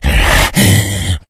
zombie_attack_6.ogg